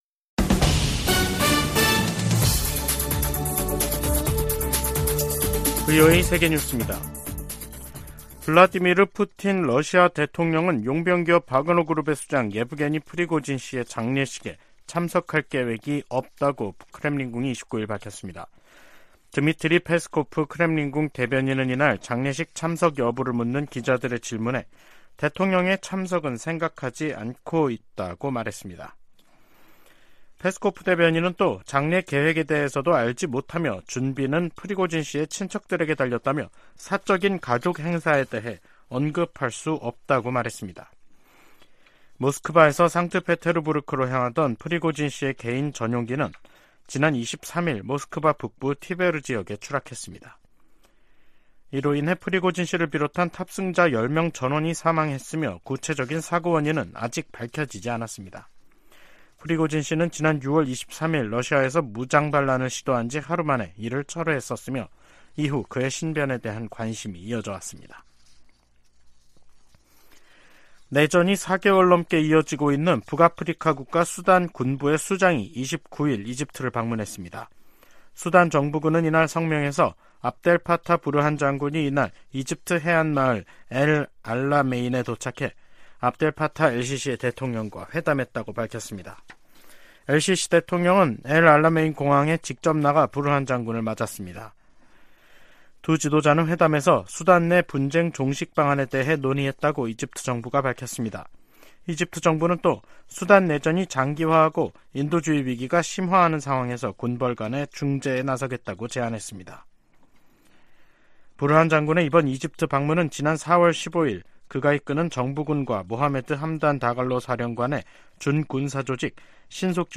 VOA 한국어 간판 뉴스 프로그램 '뉴스 투데이', 2023년 8월 29일 3부 방송입니다. 제네바에서 열린 유엔 회의에서 미국은 북한의 위성 발사가 전 세계에 대한 위협이라고 비판했습니다. 북한이 위성 발사 실패시 미국과 한국이 잔해를 수거 분석할 것을 우려해 의도적으로 로켓을 폭파시켰을 가능성이 있다고 전문가들이 분석했습니다. 김정은 북한 국무위원장이 미한일 정상들을 비난하면서 3국 합동훈련 정례화 등 합의에 경계심을 드러냈습니다.